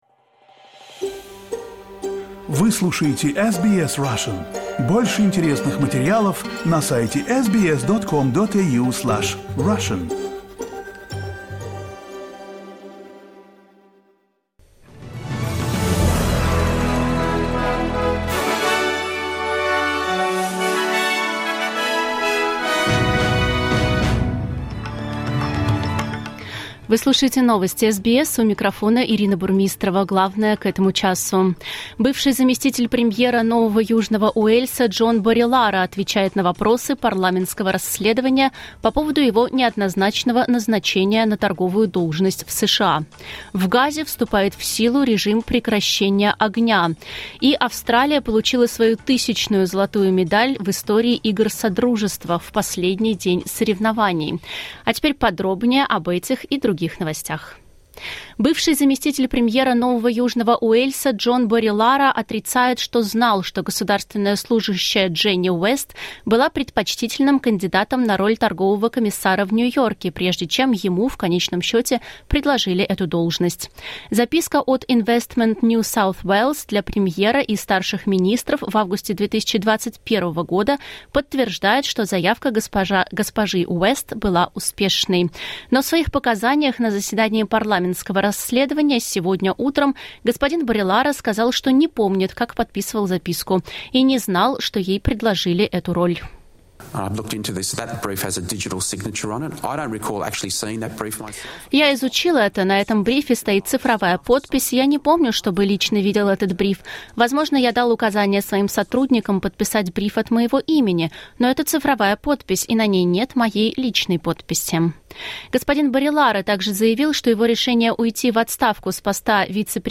SBS News in Russian - 8.08.2022